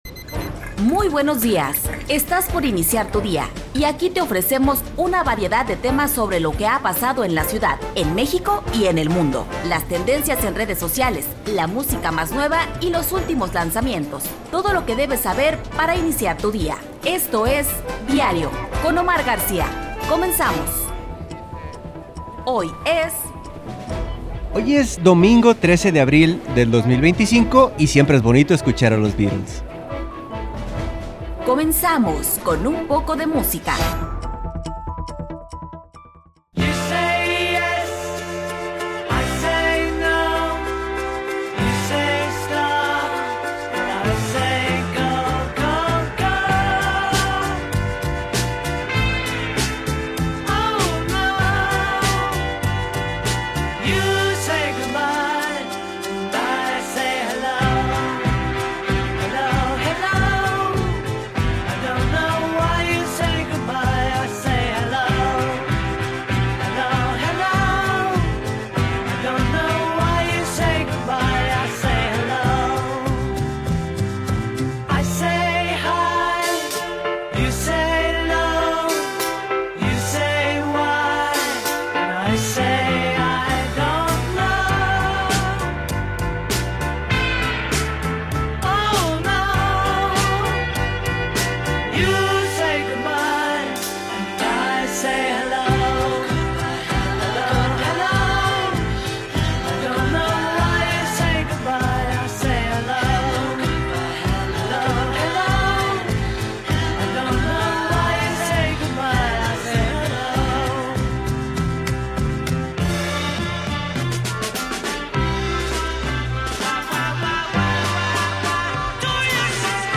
investigador y experto en temas de agua.